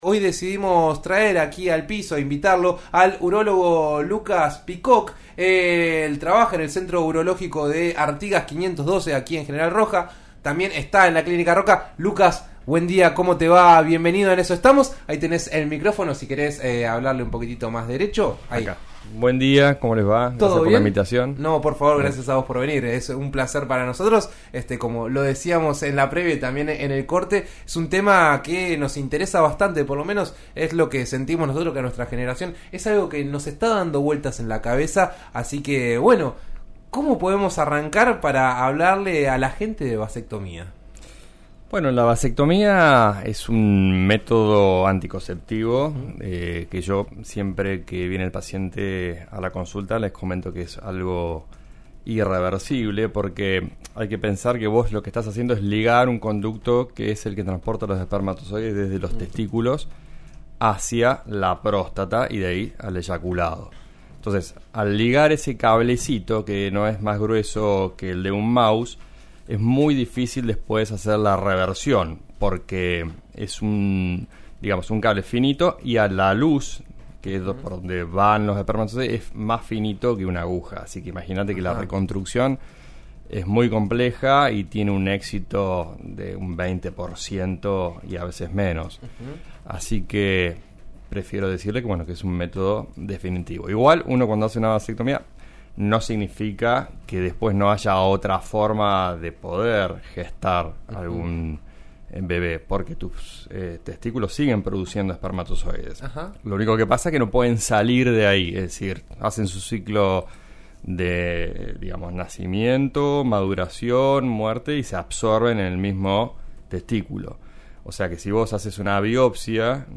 En eso estamos de RN RADIO 89.3 dialogó con el urólogo